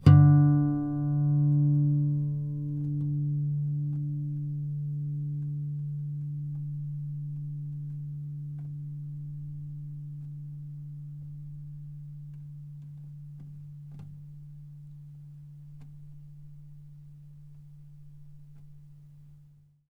harmonic-02.wav